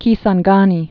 (kēsän-gänē, kĭ-zänggä-nē)